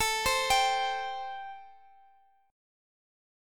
Listen to Am7 strummed